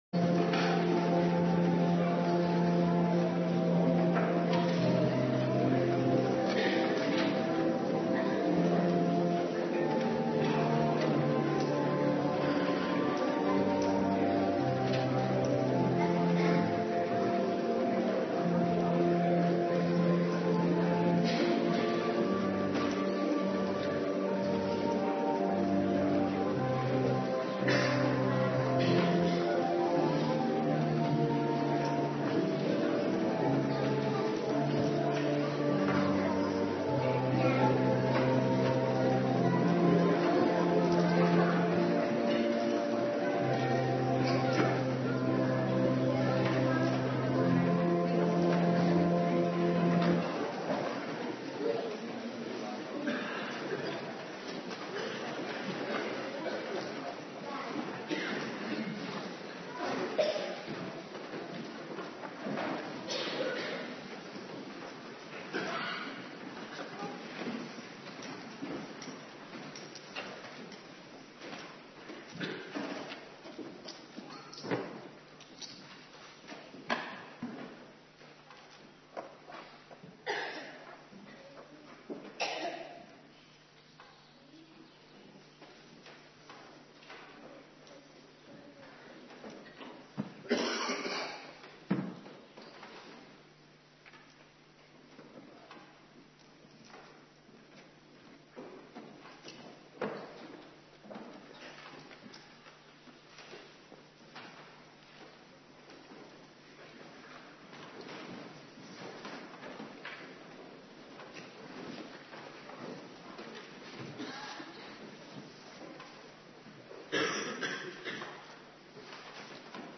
Morgendienst Eerste Pinksterdag
Locatie: Hervormde Gemeente Waarder